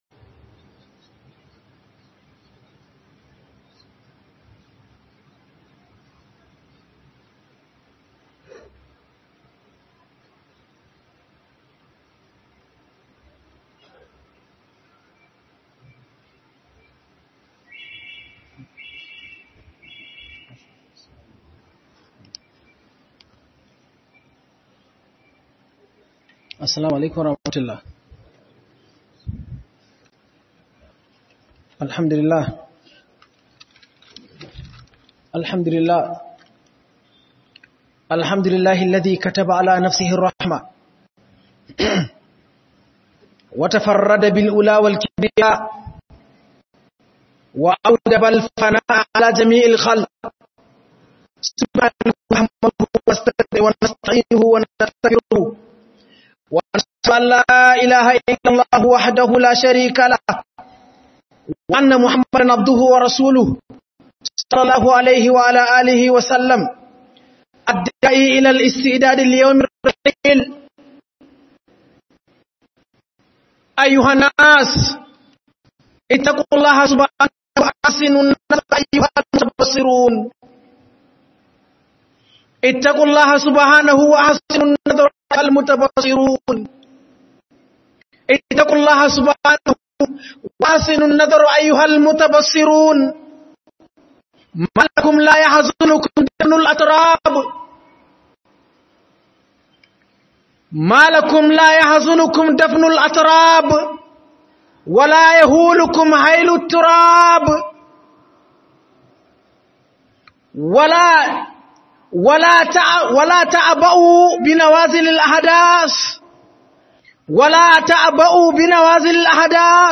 Tanadi don Kabari - HUDUBA